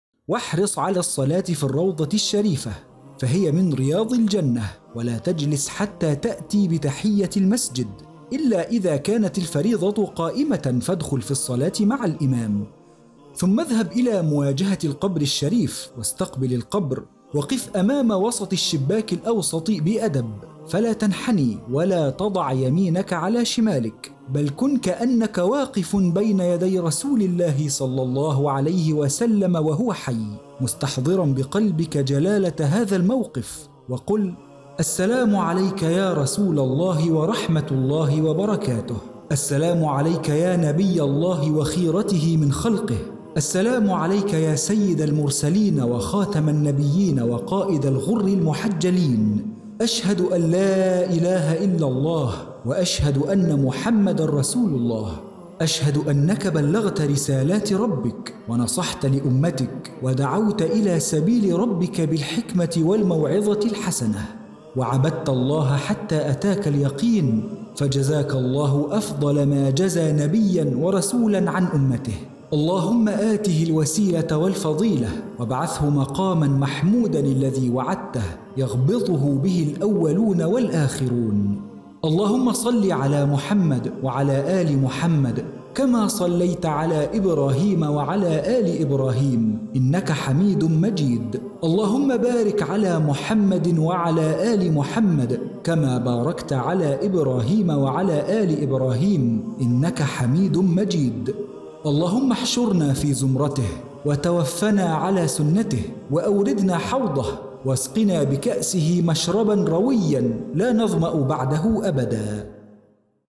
من آداب زيارة المسجد النبوي – كتاب صوتي
• ذكر
• صوت الراوي (معلق صوتي)
• العربية الفصحى
• باريتون Baritone (متوسط العرض)
• في منتصف العمر ٣٥-٥٥